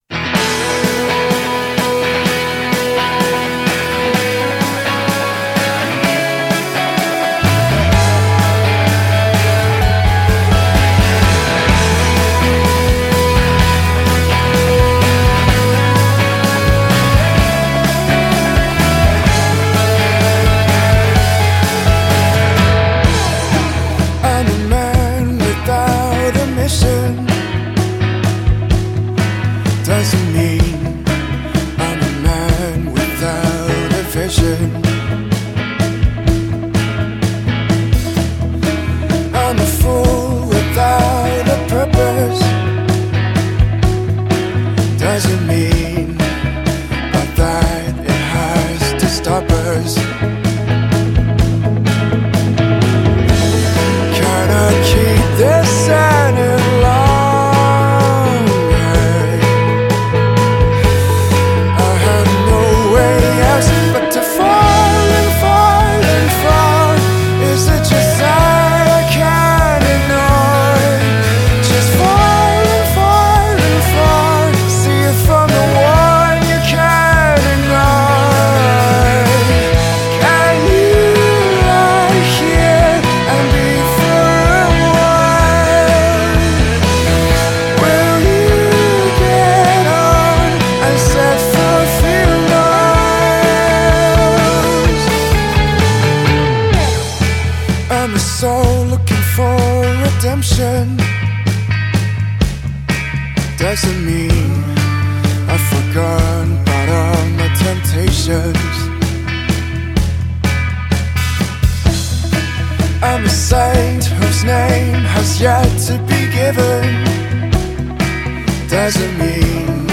呈现的曲风遍及英伦摇滚、独立摇滚、民谣摇滚等各种风格。
民谣曲风的歌声兼具温暖包容的疗愈力，低沉微带风霜的流浪氛围，加上摇滚的轻狂演绎